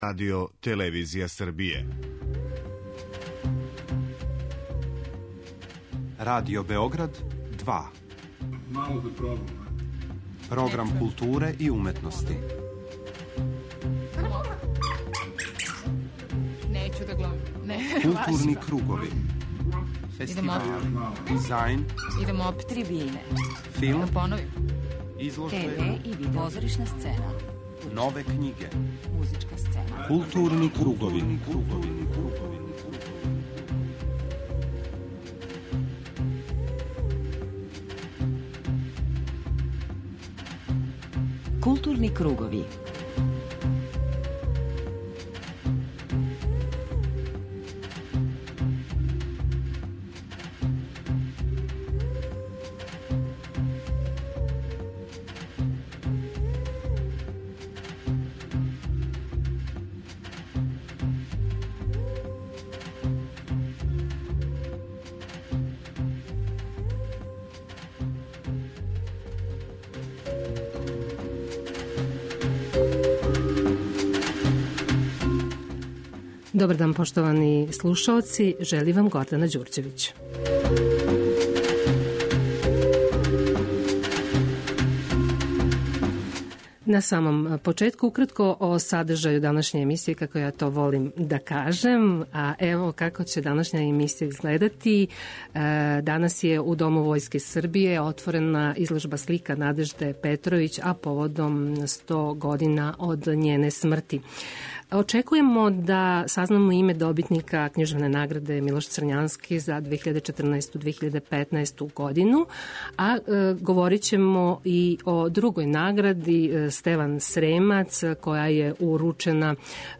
преузми : 41.19 MB Културни кругови Autor: Група аутора Централна културно-уметничка емисија Радио Београда 2.